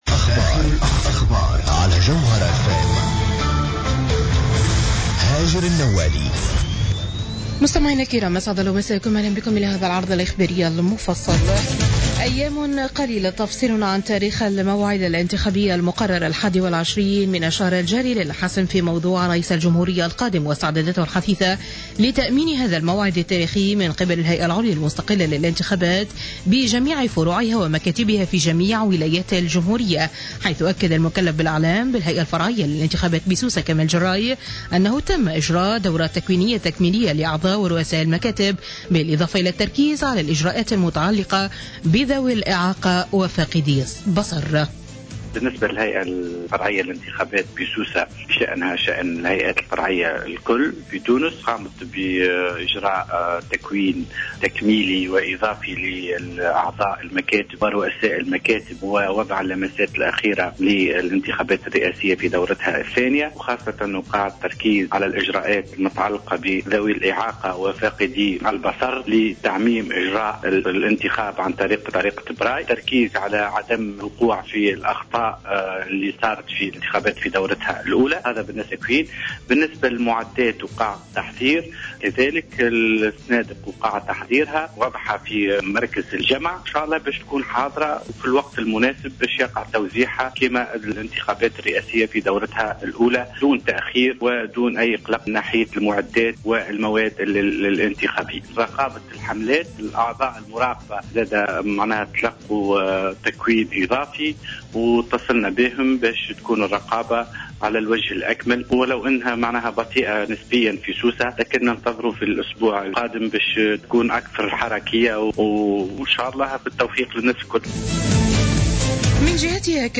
نشرة أخبار منتصف الليل ليوم 15-12-14